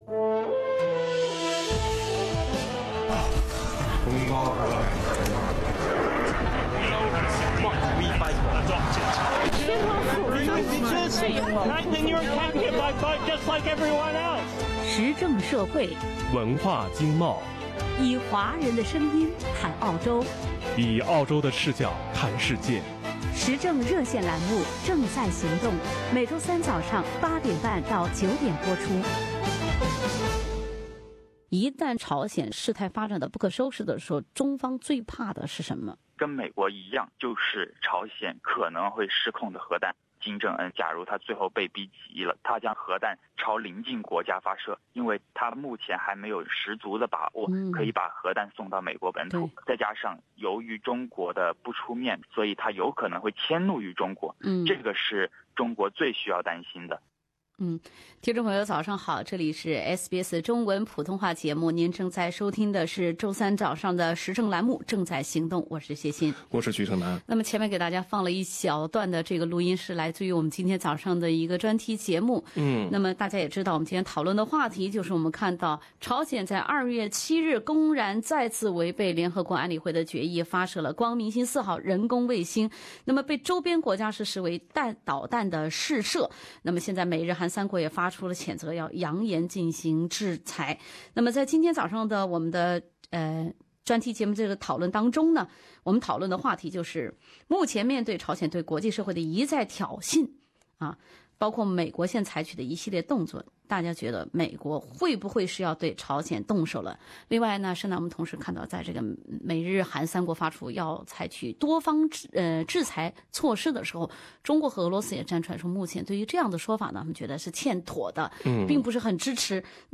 专家和听众热议朝鲜导弹危机。